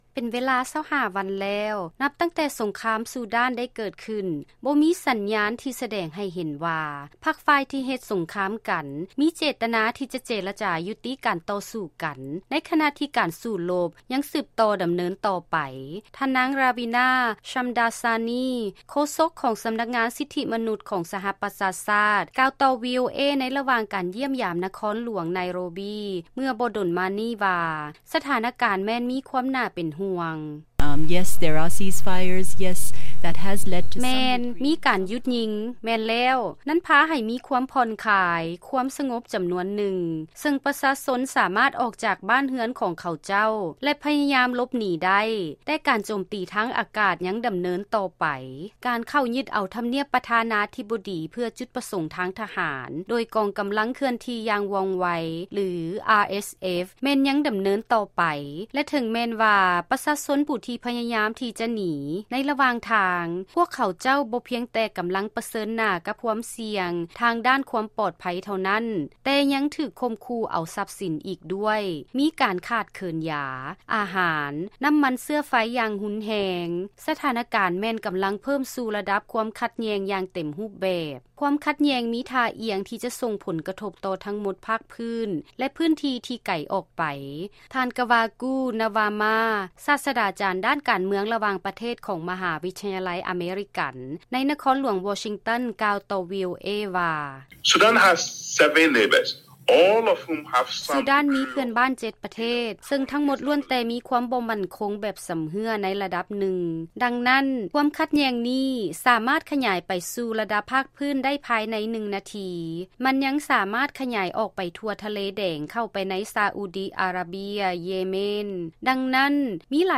ເຊີນຮັບຟັງລາຍງານ ກ່ຽວກັບ ການສູ້ລົບກັນຢູ່ໃນຊູດານ ເຮັດໃຫ້ປະເທດເພື່ອນບ້ານຕົກຢູ່ໃນຄວາມສ່ຽງ